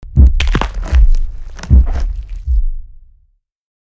049Attack_Kill.mp3